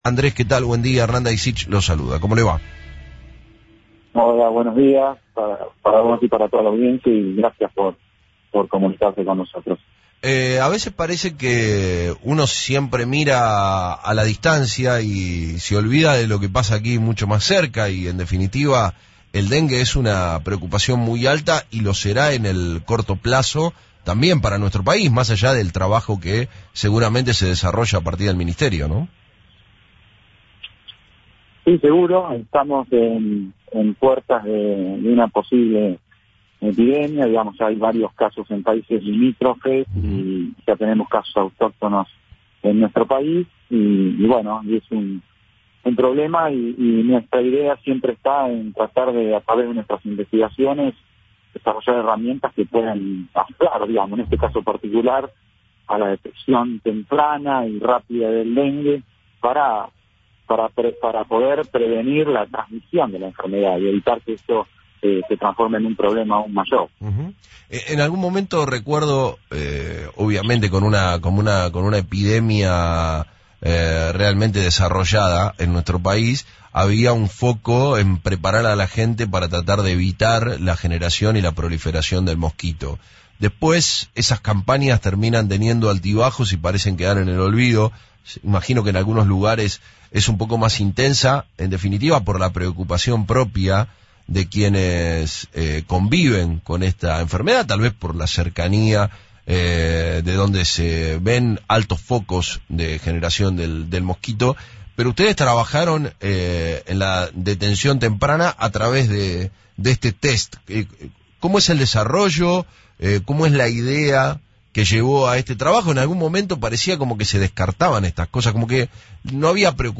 En dialogo con FRECUENCIA ZERO